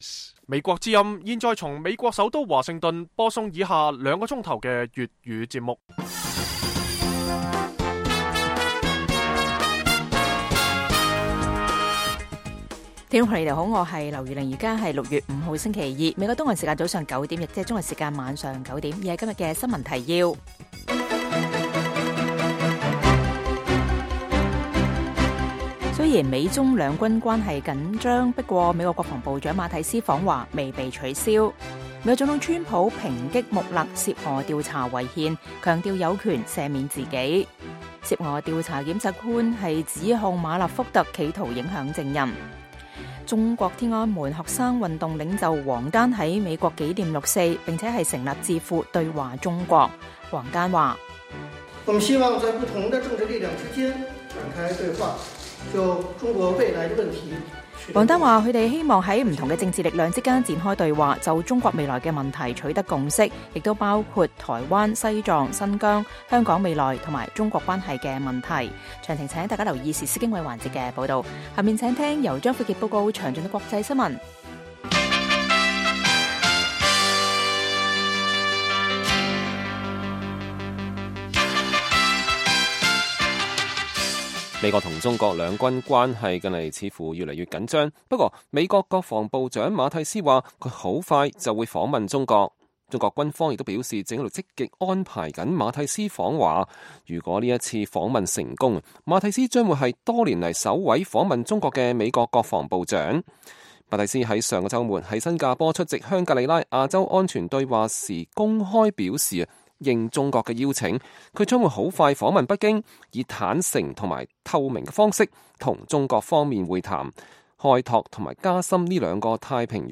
粵語新聞 晚上9-10點
北京時間每晚9－10點 (1300-1400 UTC)粵語廣播節目。